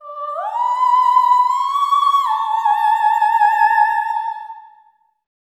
OPERATIC04-L.wav